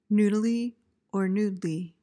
PRONUNCIATION:
(NOOD-uh-lee, NOOD-lee)